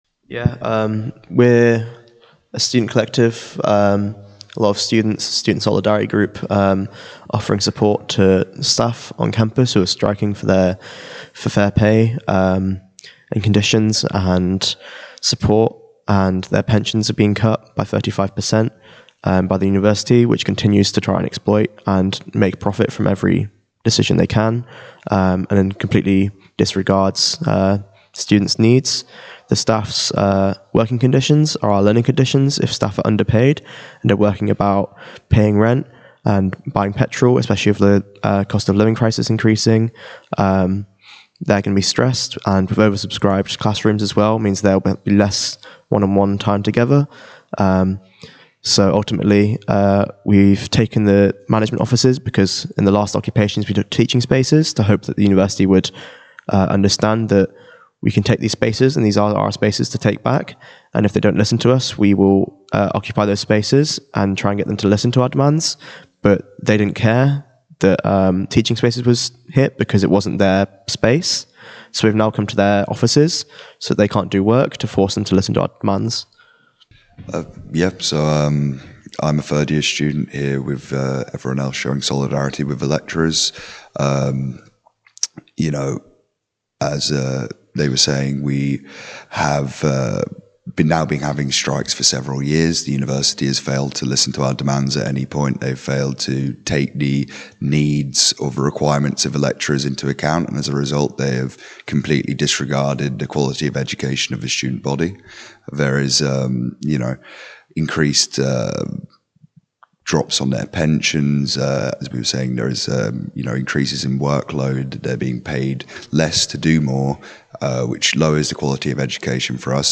Q1: Trent Building Occupiers Interview